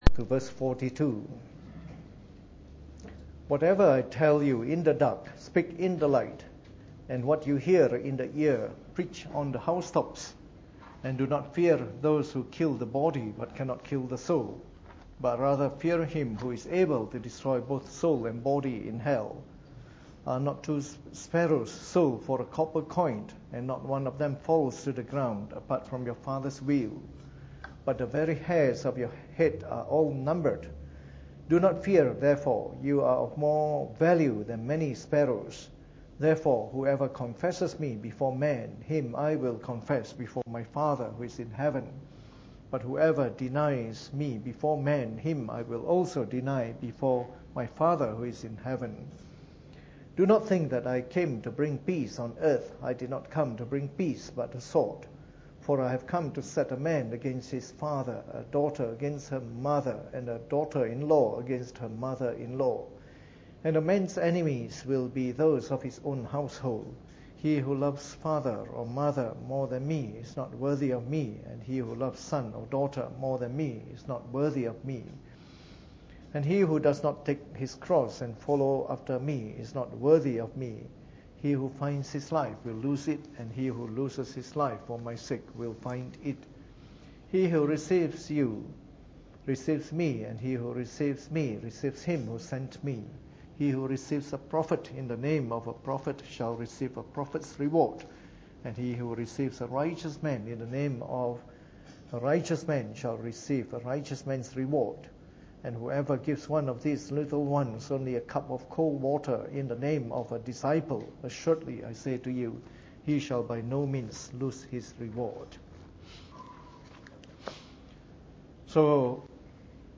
Preached on the 17th of February 2016 during the Bible Study.